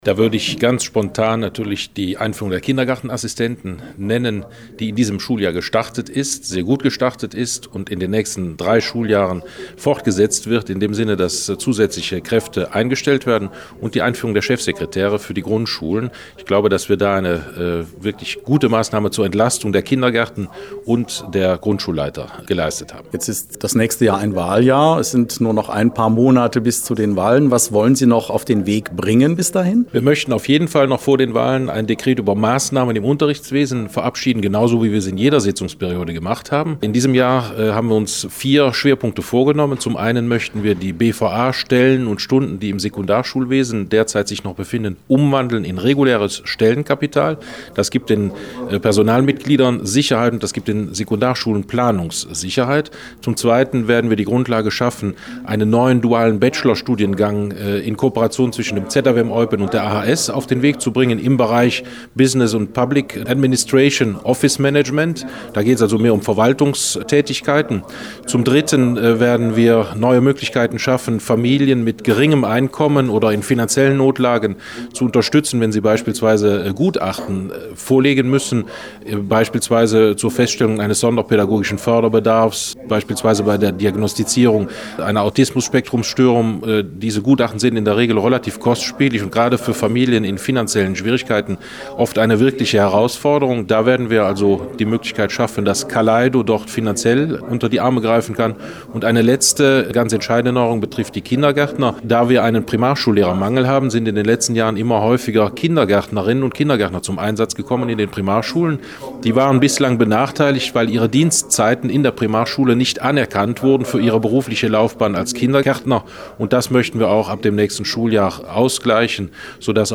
hat mit allen vier Ministern gesprochen
sprach auch mit Bildungsminister Harald Mollers: